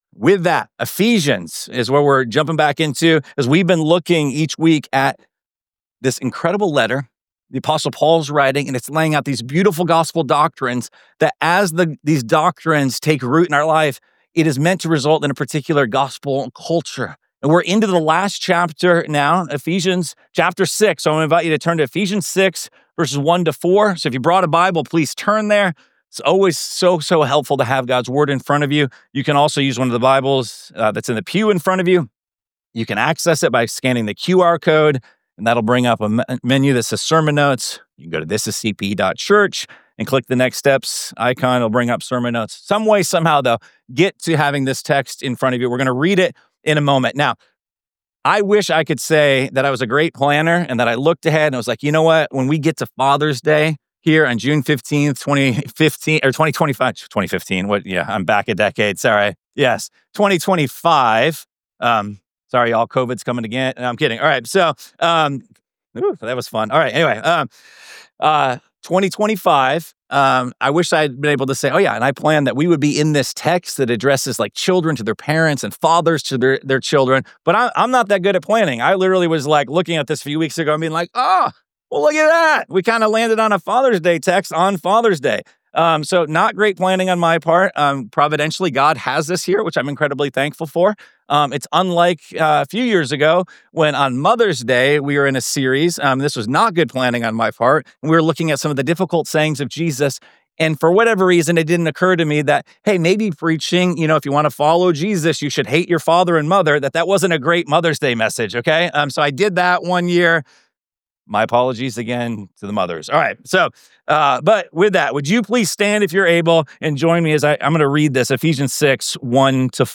6.15.25 Sermon Only- Mastered.mp3